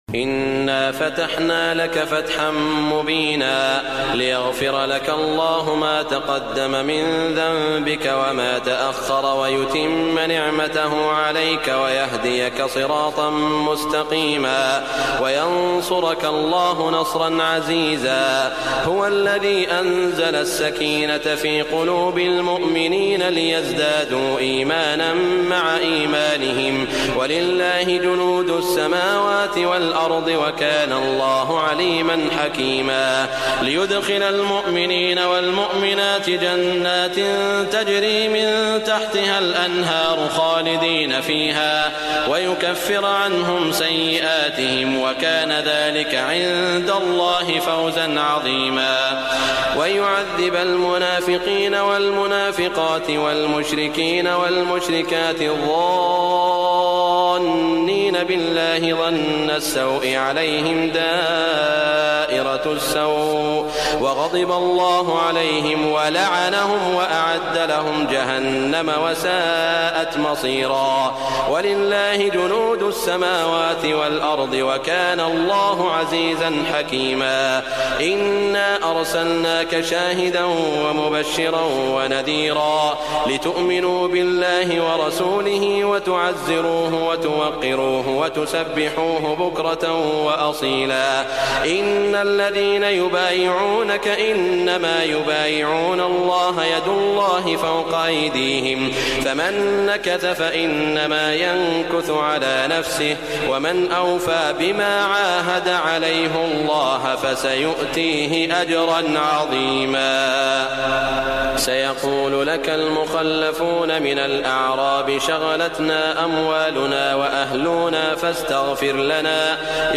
Recitation Of Quran Surah Al-Fath